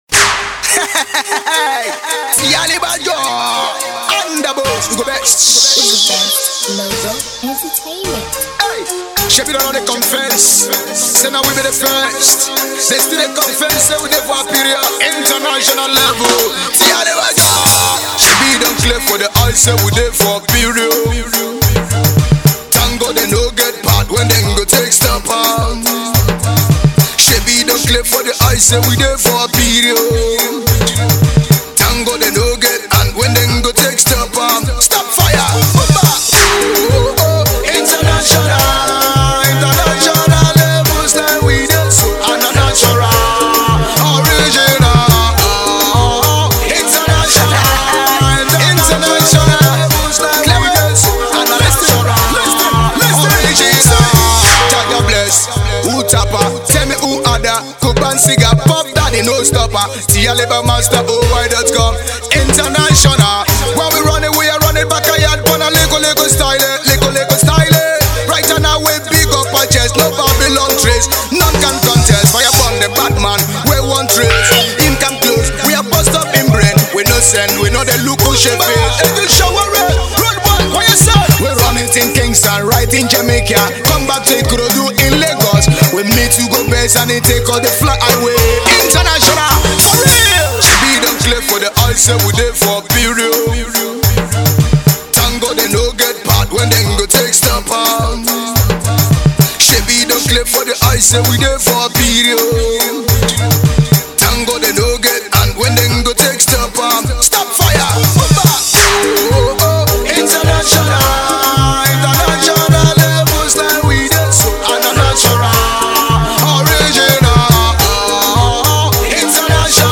afro singer